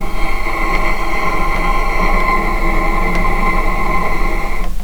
vc-C6-pp.AIF